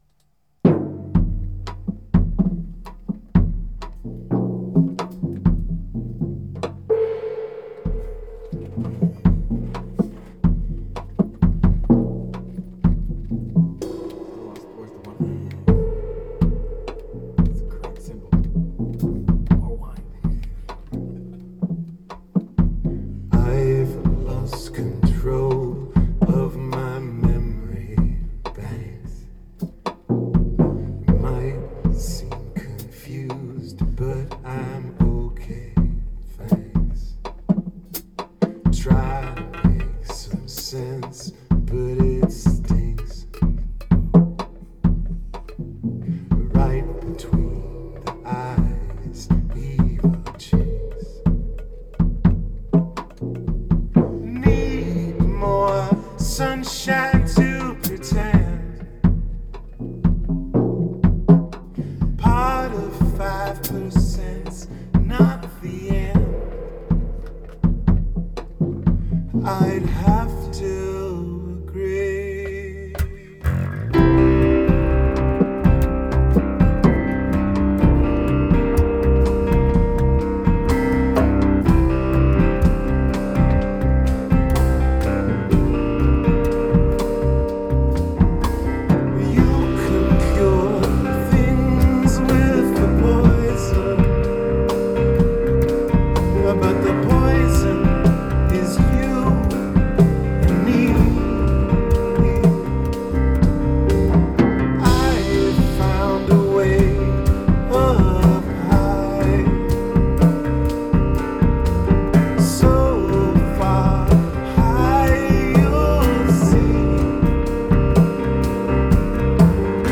Rehearsals 16.8.2013